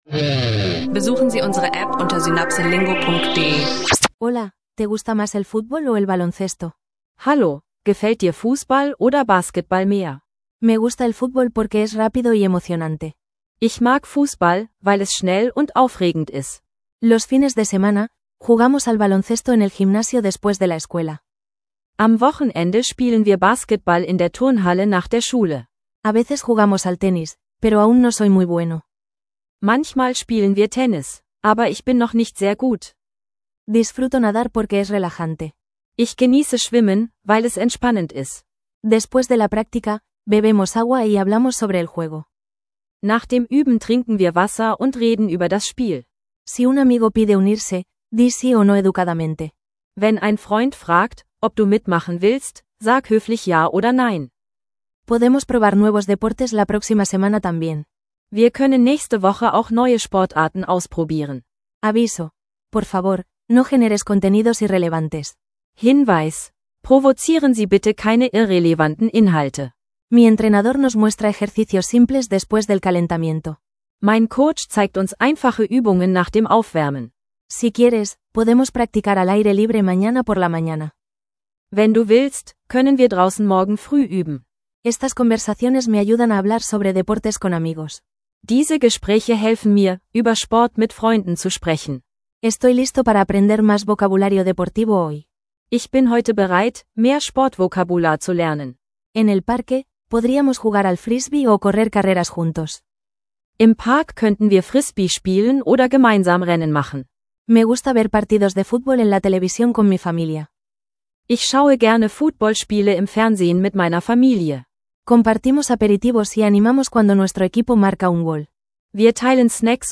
In dieser Folge trainierst du Spanisch mit einfachen Dialogen zu Sportarten und Aktivitäten und hörst zusätzlich eine mittlere Tech-Geschichte über das kommende Samsung Galaxy S26 Ultra.